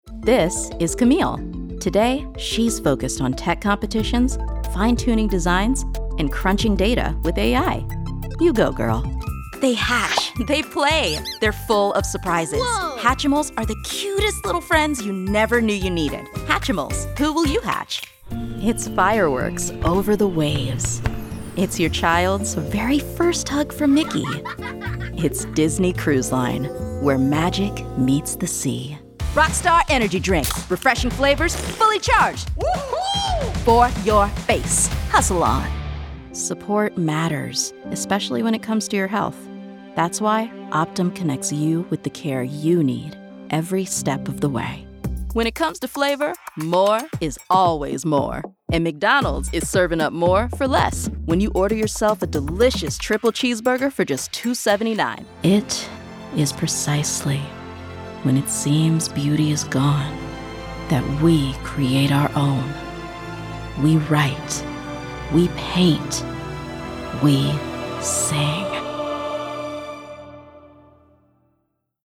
Voiceover
Commercial